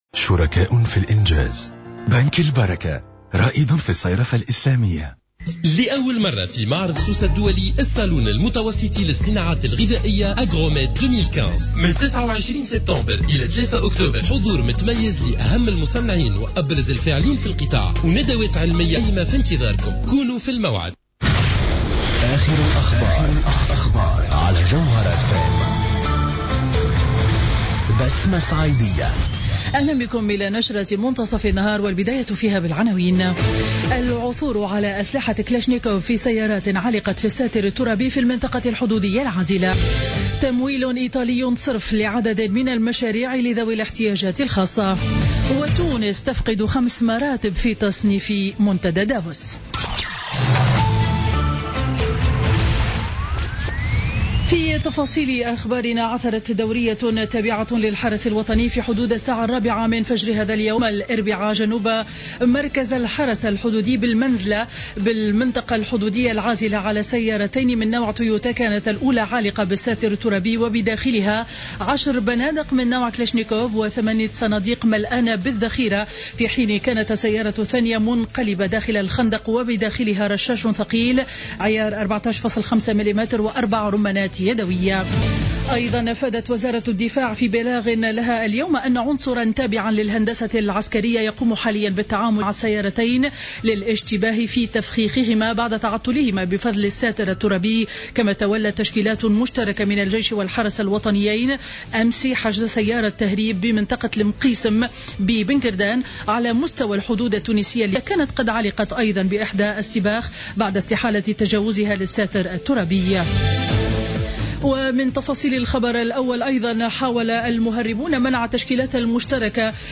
نشرة أخبار منتصف النهار ليوم الأربعاء 30 سبتمبر 2015